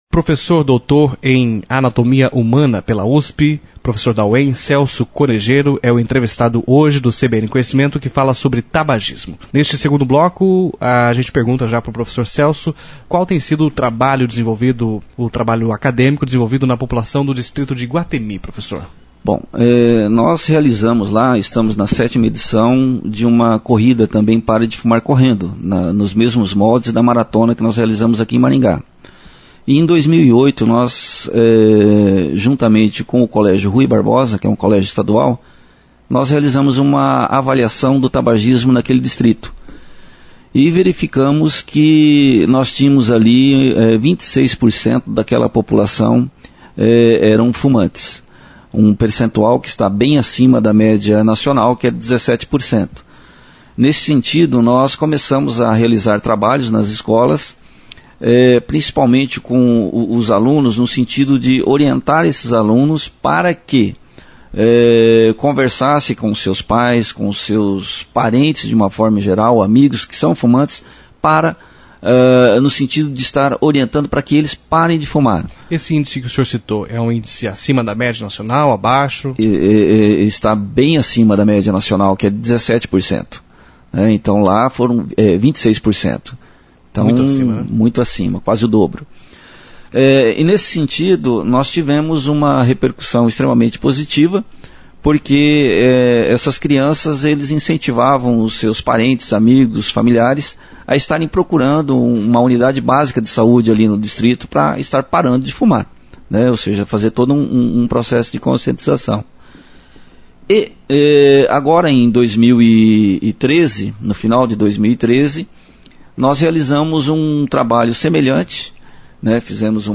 entrevista-na-cbn-parte-2